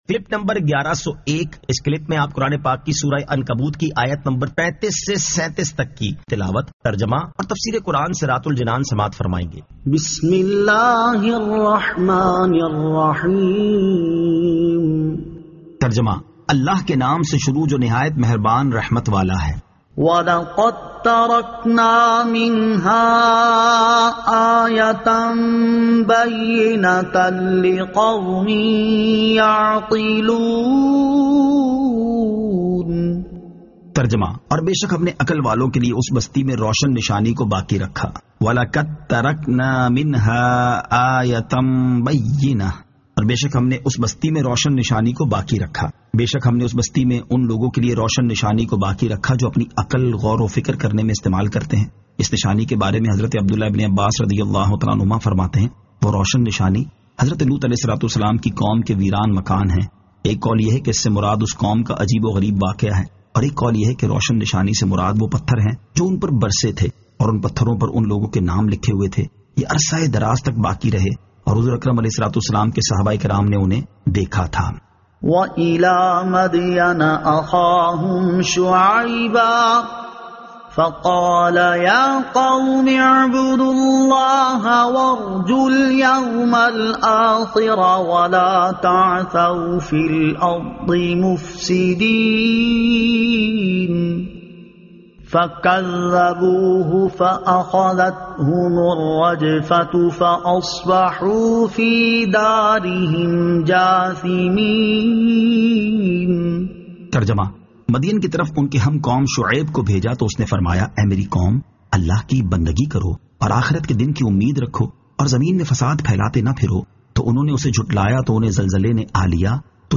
Surah Al-Ankabut 35 To 37 Tilawat , Tarjama , Tafseer